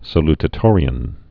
(sə-ltə-tôrē-ən)